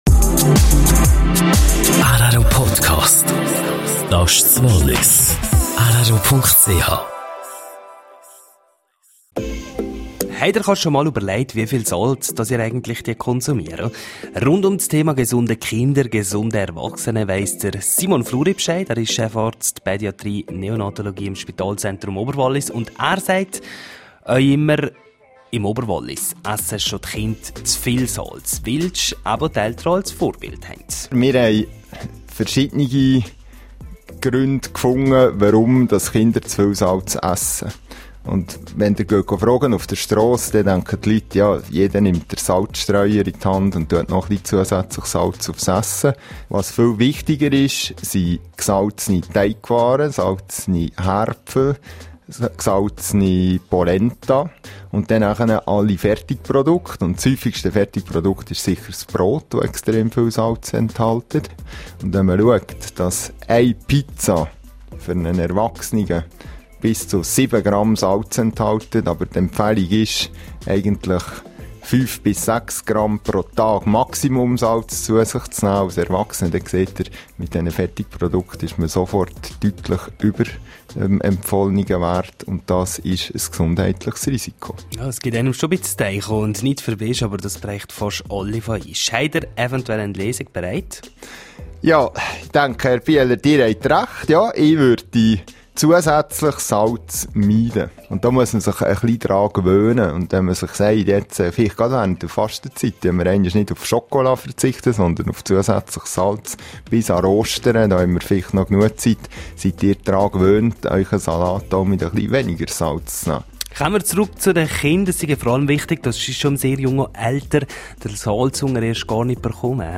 Interview zum Thema Salz